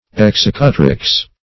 Search Result for " executrix" : Wordnet 3.0 NOUN (1) 1. a woman executor ; The Collaborative International Dictionary of English v.0.48: Executrix \Ex*ec"u*trix\, n. [LL.] (Law) A woman exercising the functions of an executor.